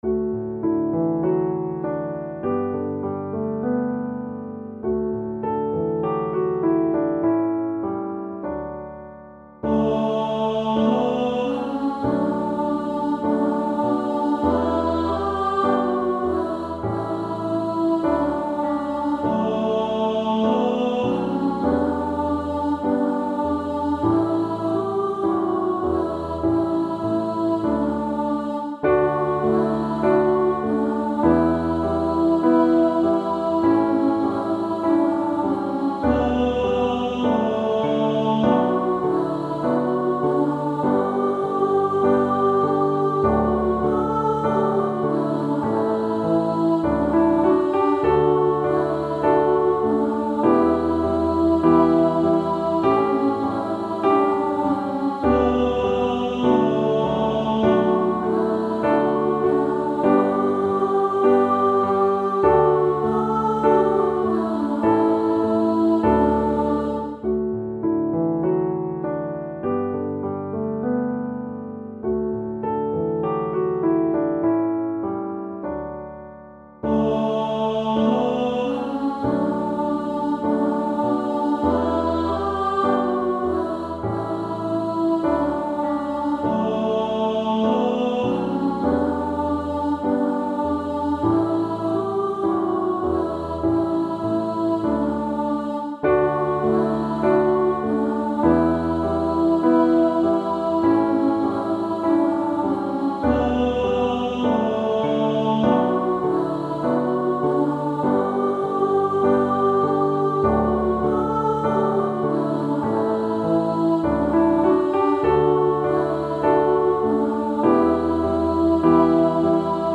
Podkład muzyczny